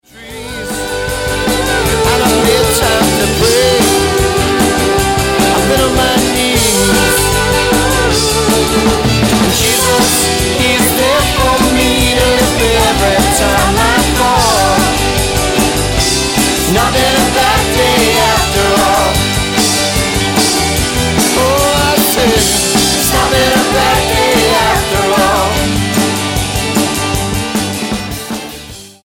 Rock Album